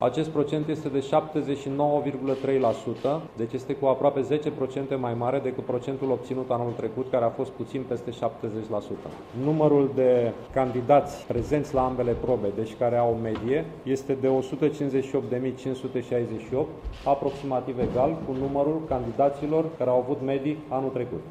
Sorin Cîmpeanu, ministrul Educației:
campeanu.mp3